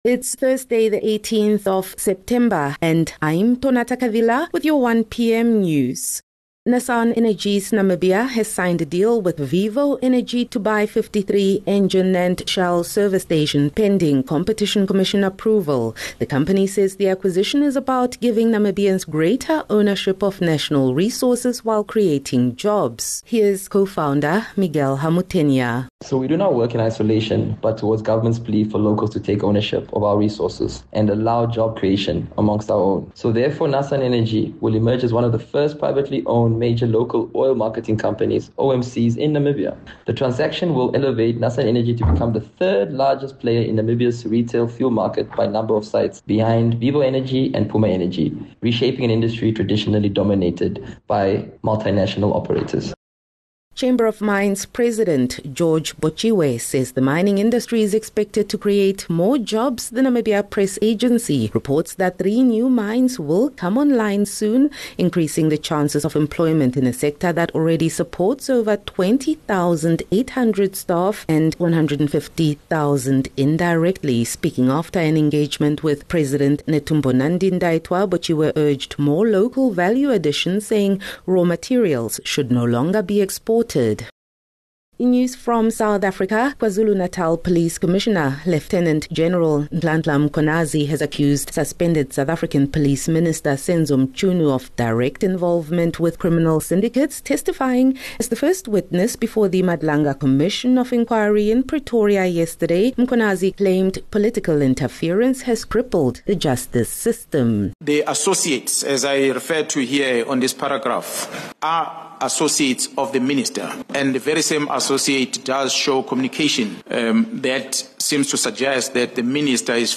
18 Sep 18 September - 1 pm news